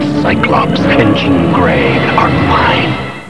From the X-Men Animated Series.